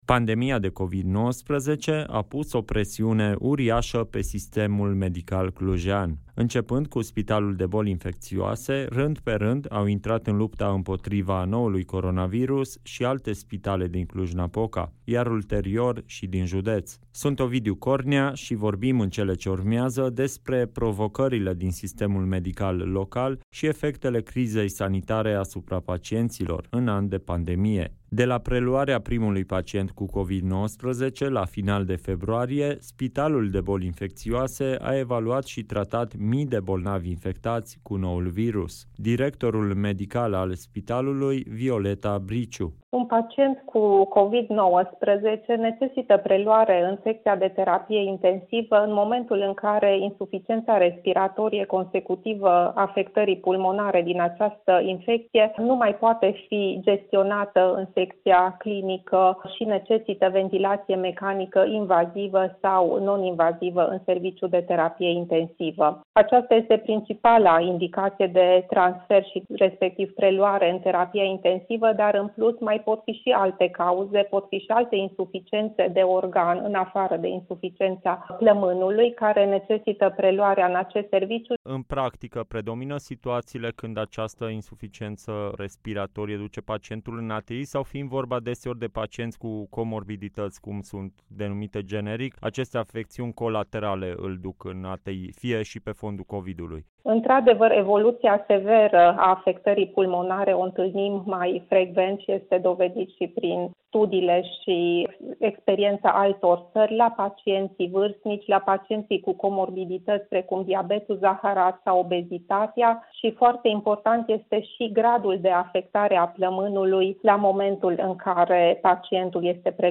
experți din sistemul medical clujean.